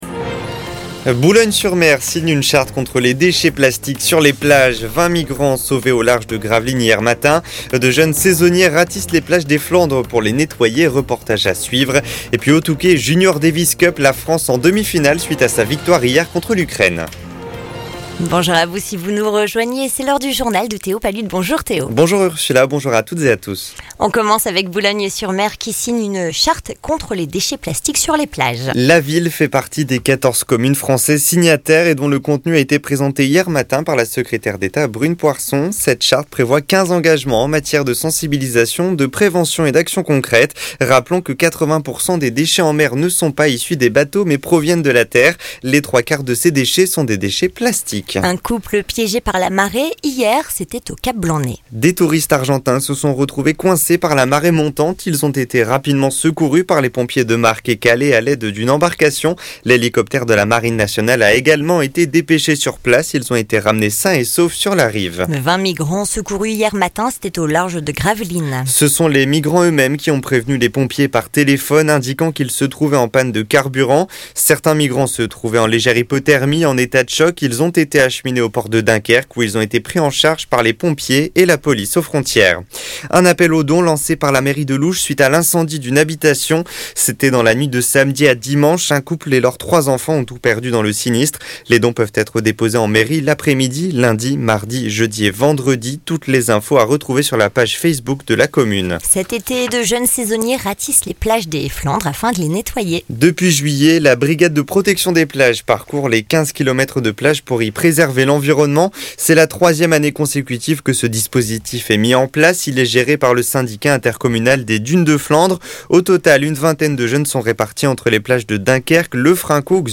Le journal du mardi 6 août